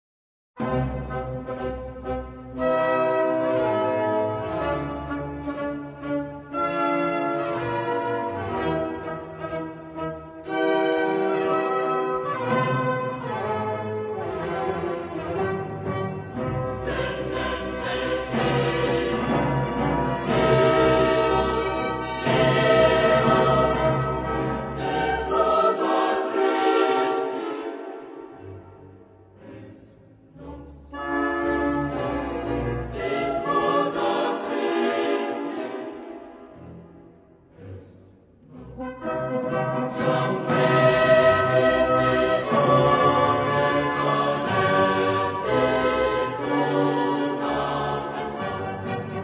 Genre-Stil-Form: geistlich ; klassisch ; Hymnus (geistlich)
Charakter des Stückes: poetisch
Chorgattung: SATB  (4 gemischter Chor Stimmen )
Solisten: SATB  (4 Solist(en))
Instrumentation: klassisches Orchester  (19 Instrumentalstimme(n))
Instrumente: Flöte (2) ; Oboe (2) ; Fagott (2) ; Horn (2) ; Clarino (2) ; Pauken (2) ; Posaune (3) ; Violinen (2) ; Viola (div) ; Violoncello/Kontrabaß ; Orgel (1)
Tonart(en): C-Dur